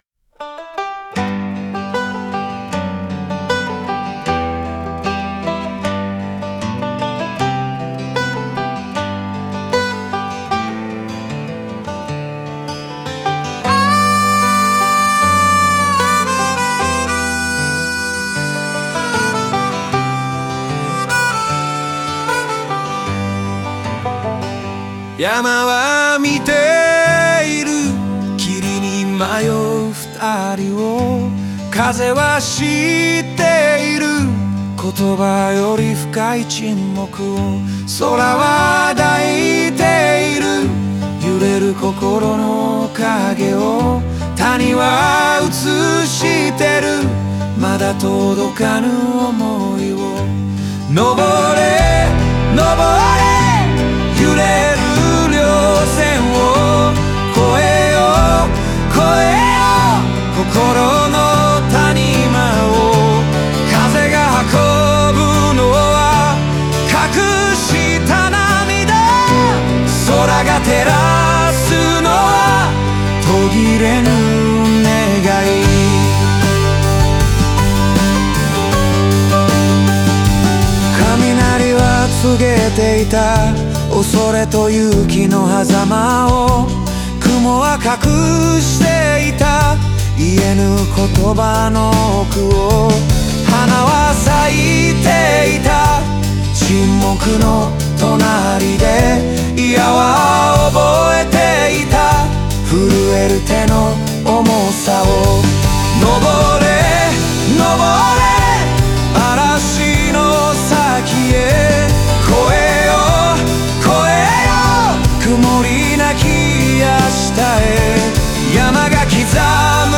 オリジナル曲♪
この歌詞は、二人の恋人が夏山を登る物語を、自然そのものの声を通して描いた寓話的なフォークソングだ。